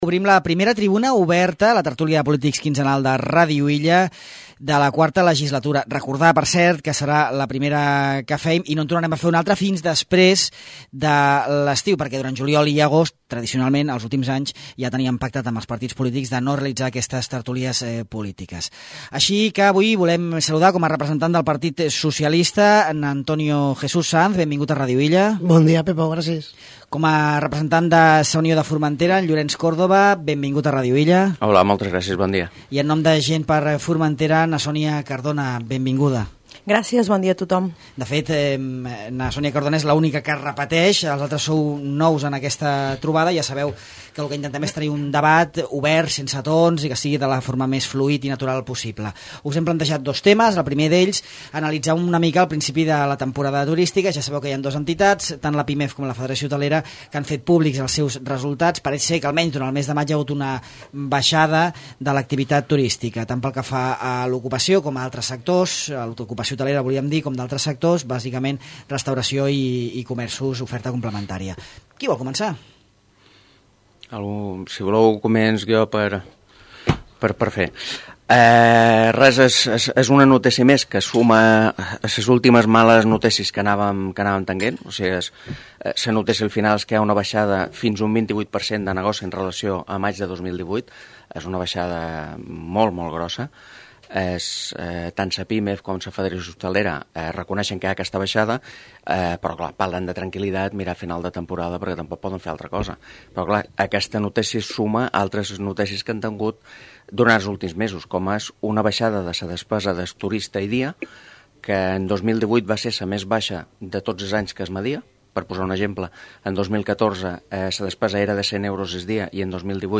Sònia Cardona (GxF), Llorenç Córdoba (Sa Unió) i Antonio J. Sanz (PSOE) participen en la Tribuna Oberta, el debat quinzenal del De Far a Far amb els representants polítics del Ple insular. La primera tertúlia política de la IV legislatura del Consell ha abordat les dades turístiques del mes de maig i la problemàtica de l’accés a l’habitatge.